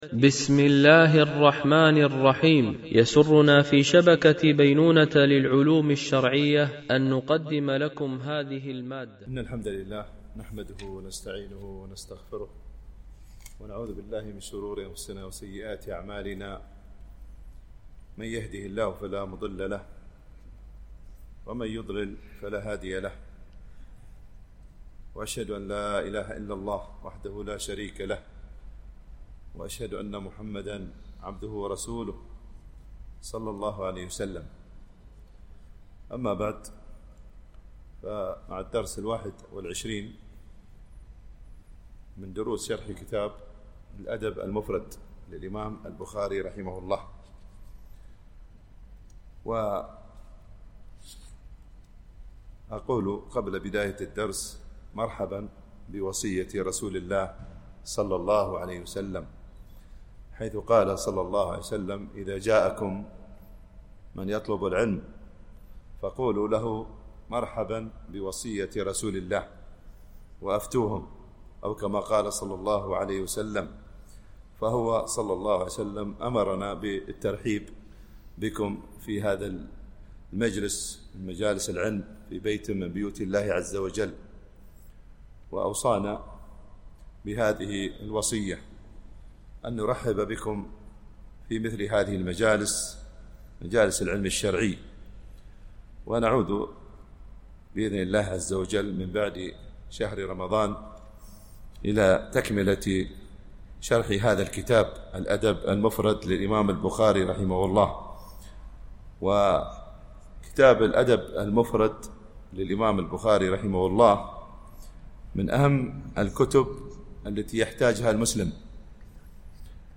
الشرح الثاني للأدب المفرد للبخاري - الدرس 21 ( الحديث 70 - 72 )